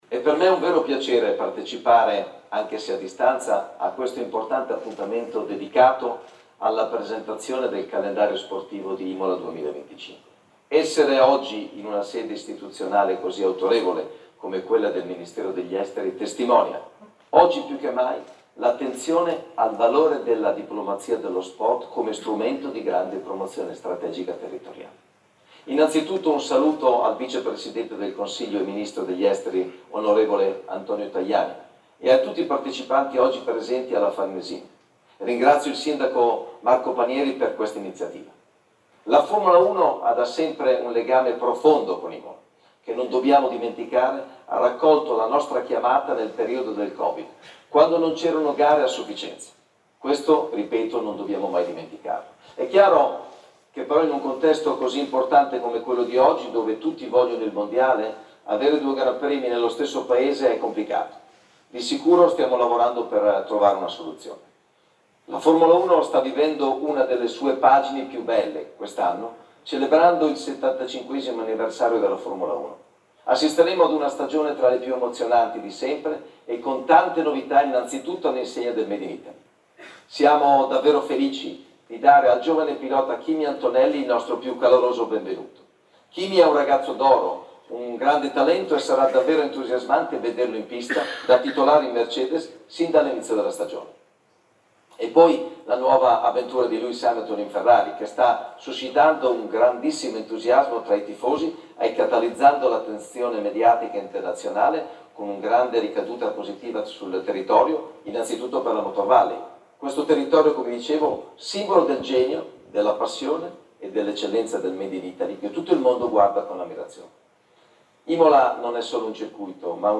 Stefano Domenicali, Presidente e CEO della F1, al microfono